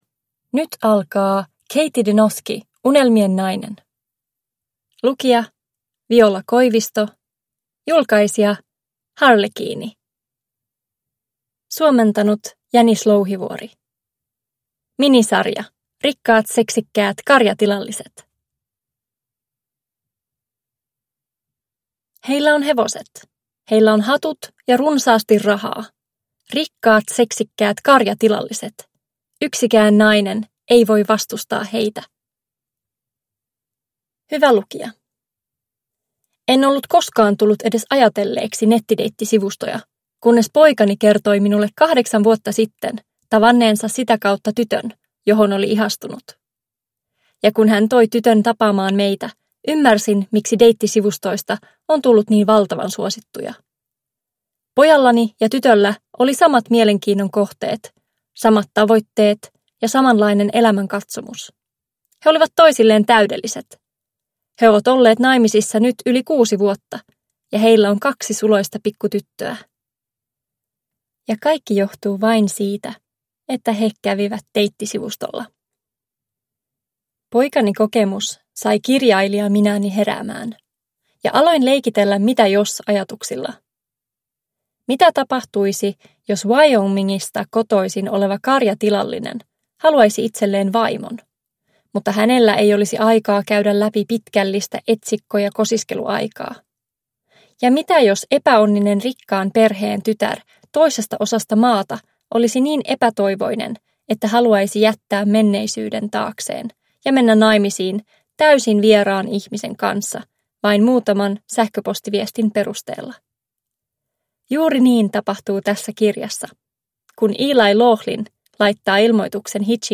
Unelmien nainen (ljudbok) av Kathie DeNosky